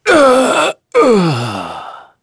Crow-Vox_Dead.wav